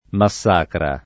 [ ma SS# kra ]